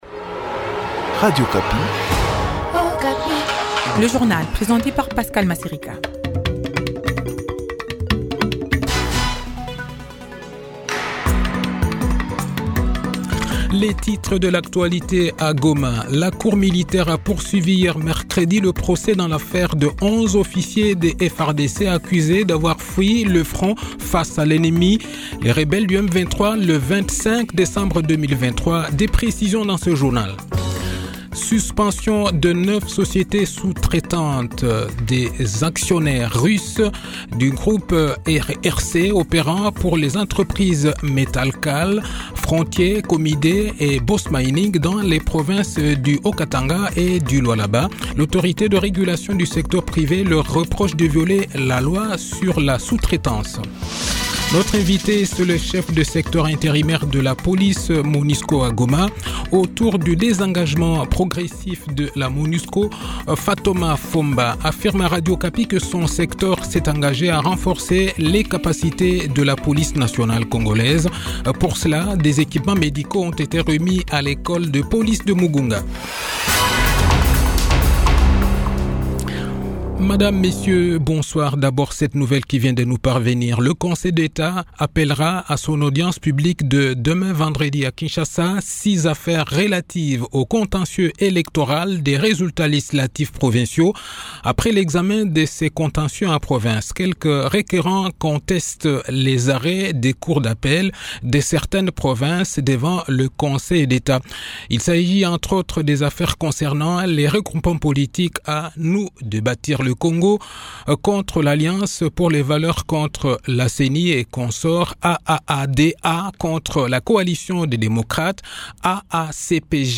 Le journal de 18 h, 14 mars 2024